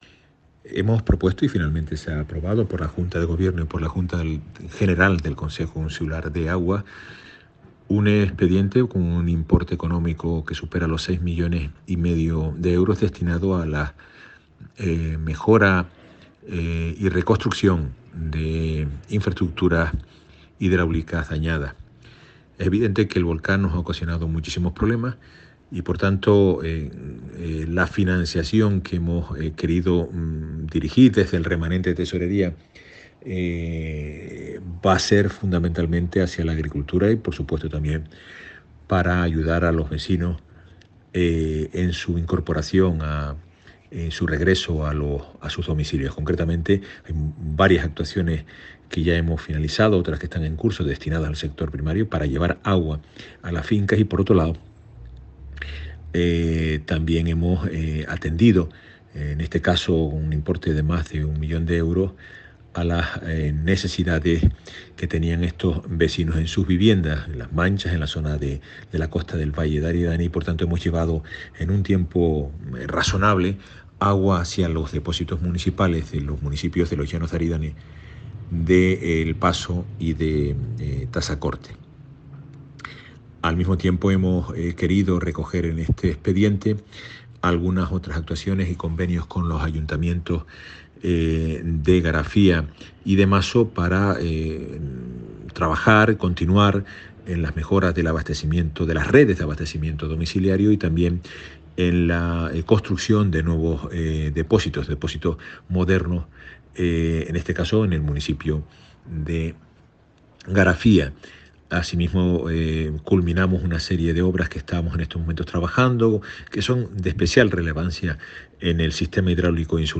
Declaraciones Carlos Cabrera.mp3